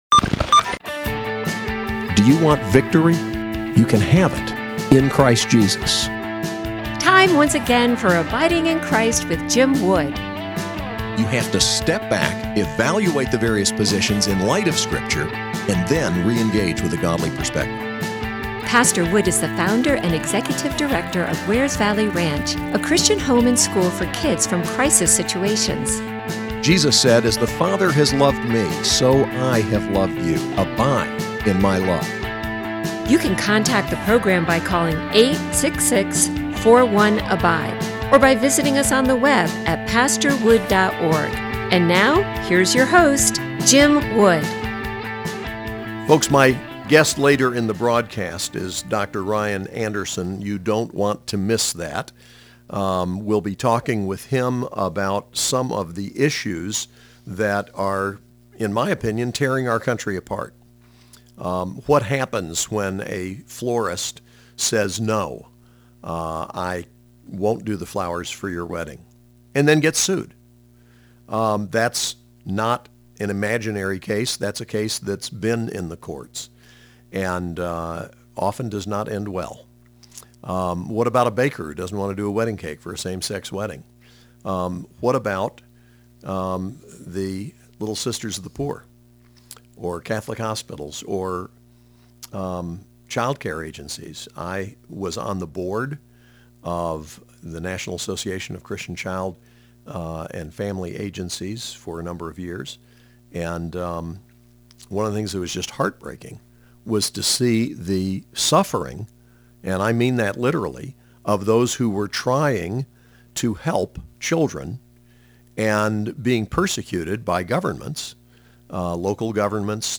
Interview: Ryan T. Anderson, The Public Discourse